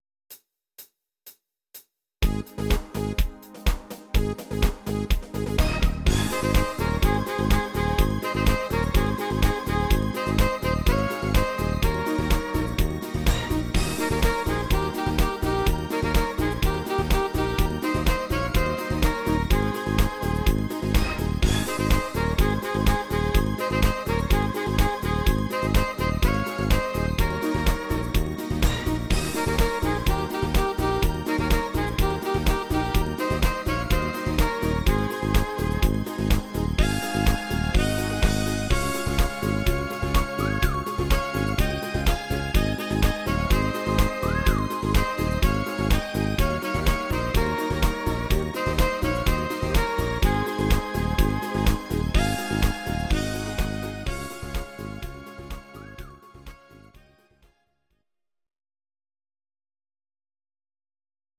These are MP3 versions of our MIDI file catalogue.
Please note: no vocals and no karaoke included.
party version